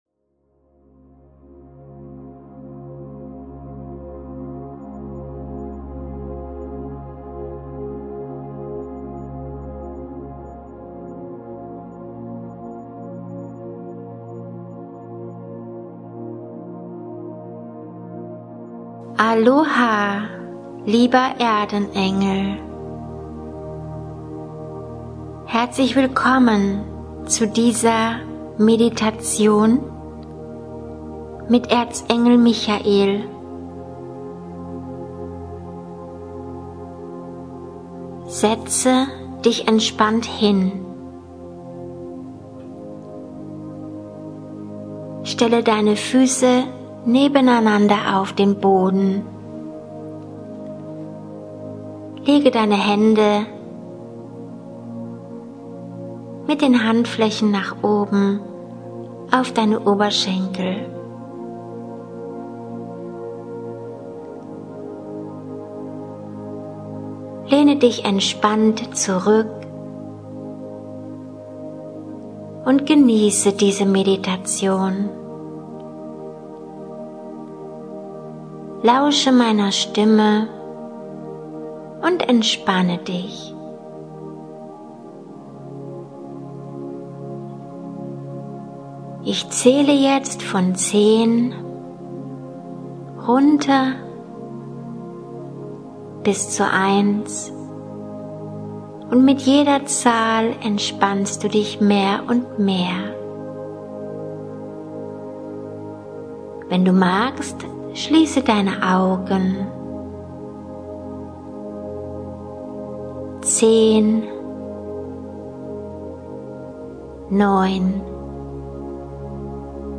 Traumhafte Stimme: